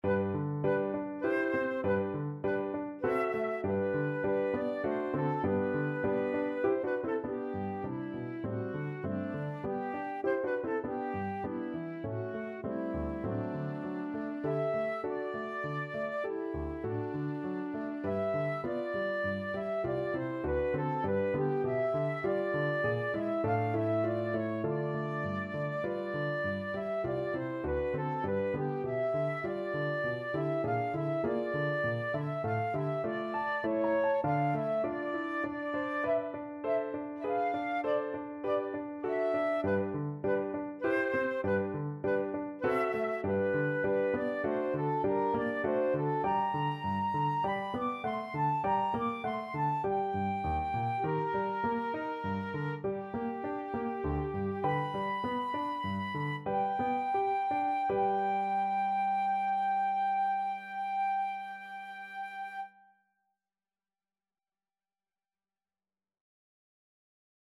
Flute version
3/4 (View more 3/4 Music)
Classical (View more Classical Flute Music)